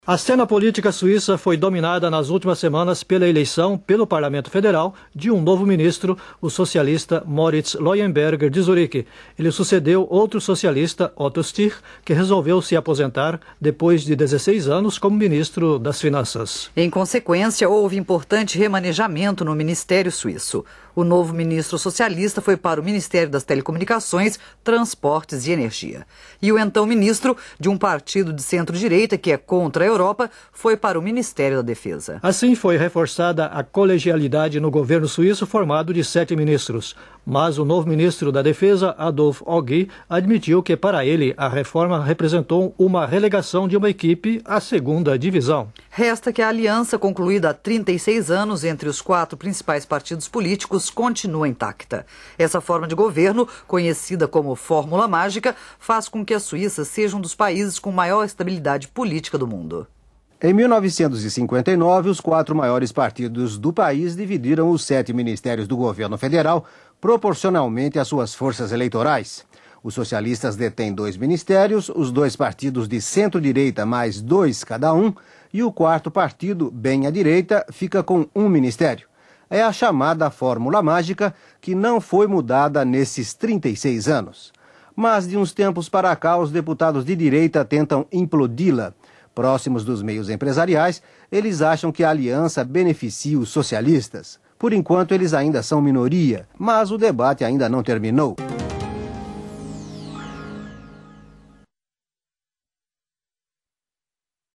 Arquivo de 1995 da Rádio Suíça Internacional.